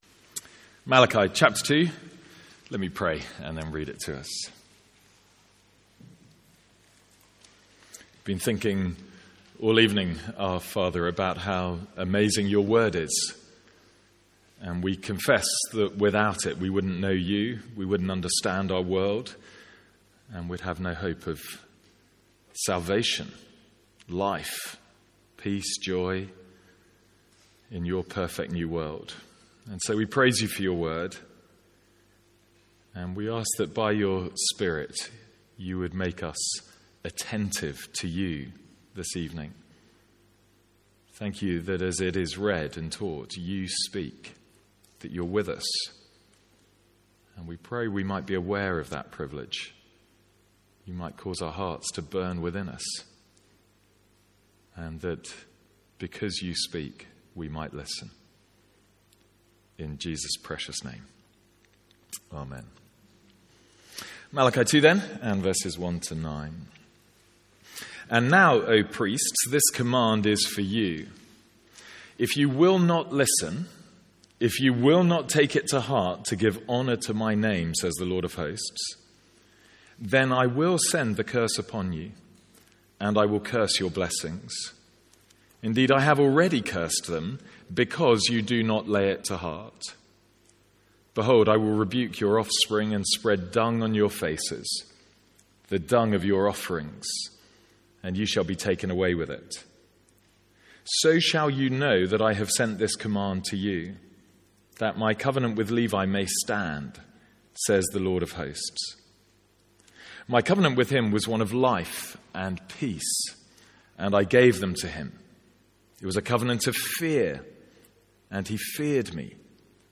Sermons | St Andrews Free Church
From our evening series in Malachi.